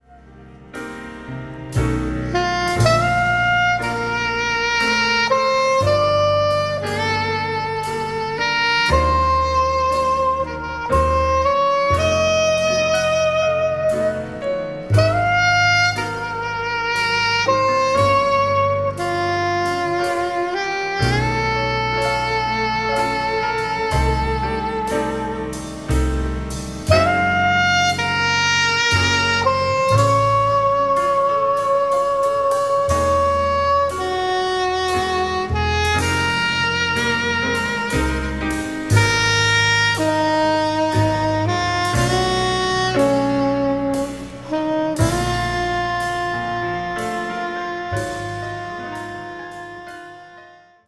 After all the raging, how about a nice ballad?